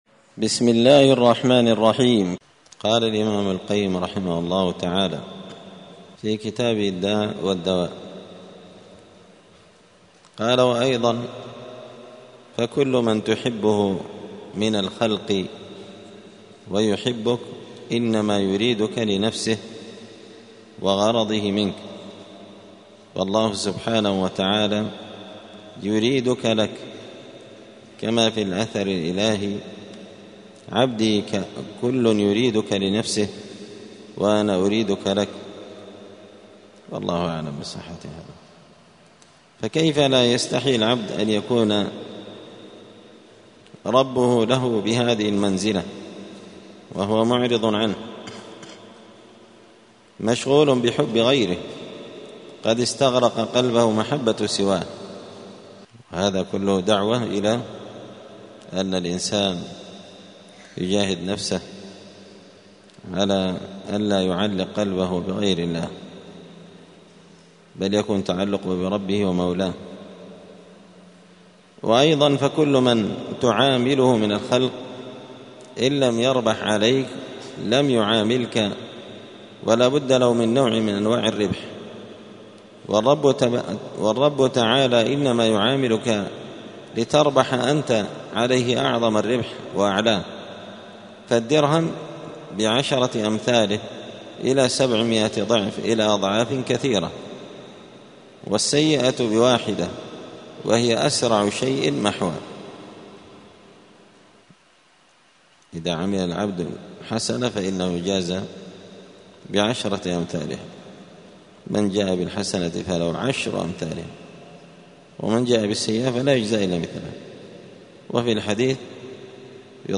*الدرس الثاني والتسعون (92) (فصل: كمال اللذة في كمال المحبوب وكمال المحبة)*